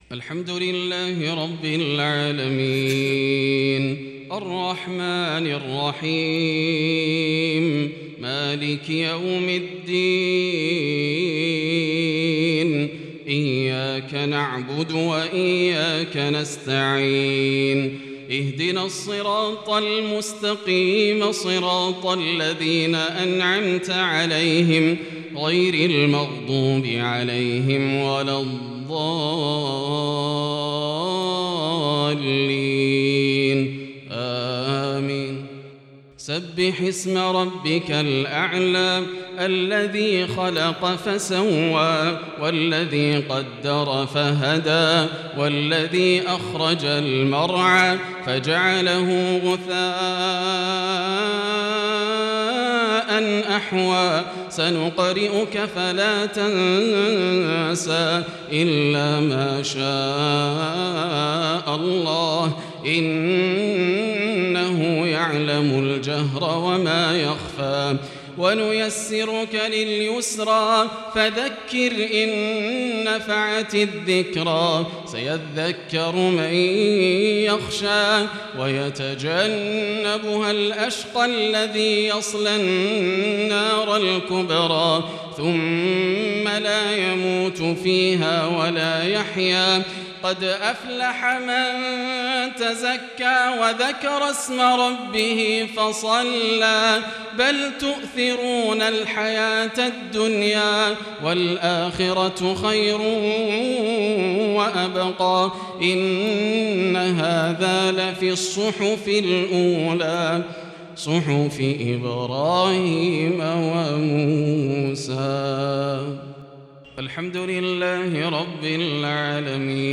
صـلاة المغرب الشيخان فيصل غزاوي وصلاح البدير